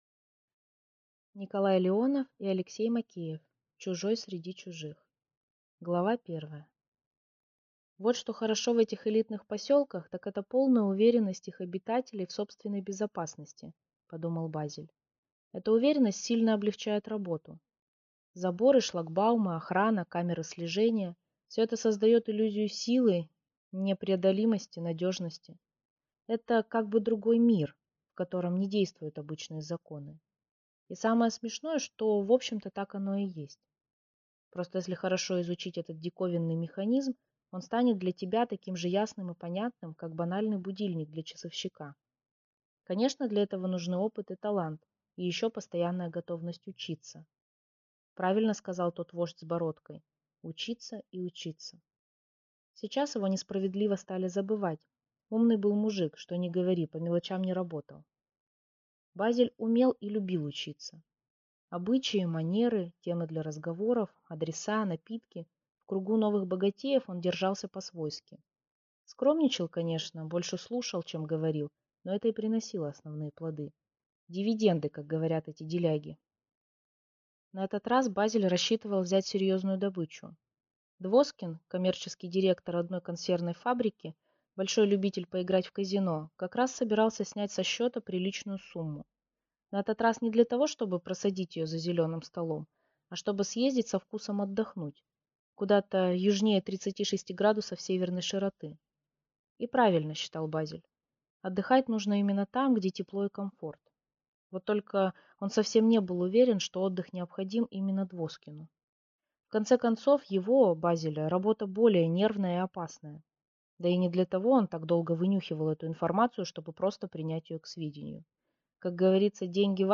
Аудиокнига Чужой среди чужих | Библиотека аудиокниг